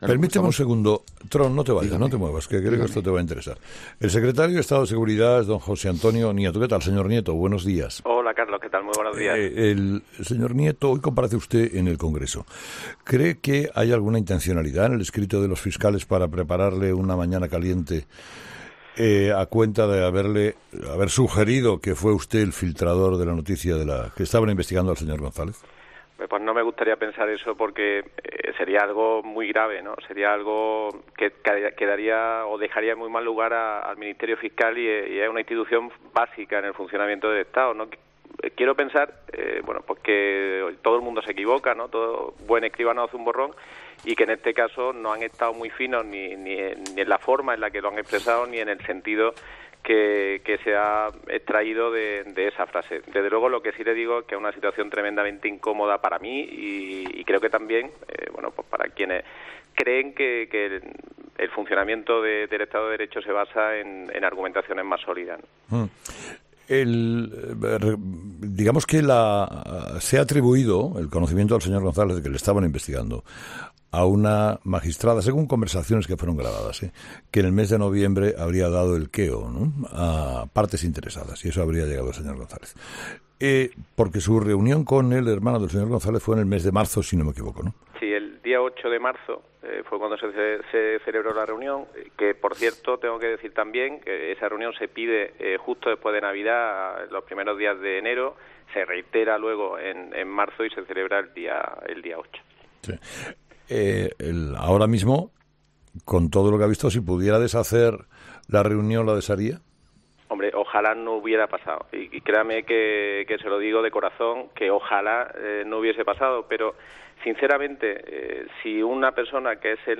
Escucha la entrevista a José Antonio Nieto, secretario de Estado de Seguridad en 'Herrera en COPE'